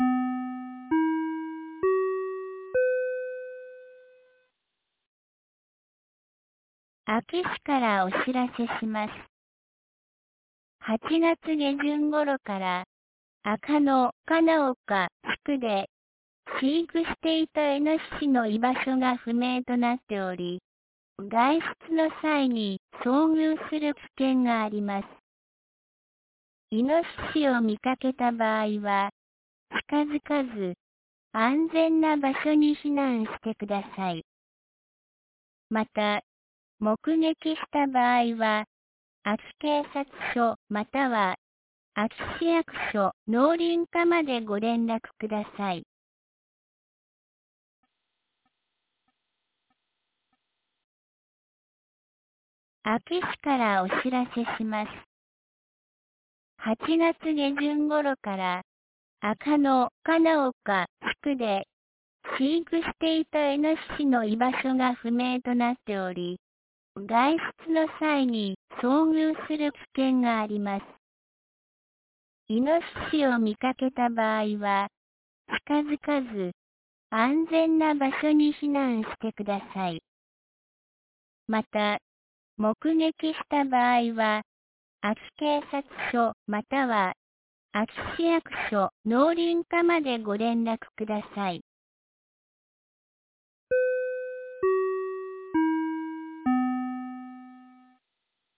2024年09月13日 16時31分に、安芸市より赤野、穴内へ放送がありました。